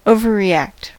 overreact: Wikimedia Commons US English Pronunciations
En-us-overreact.WAV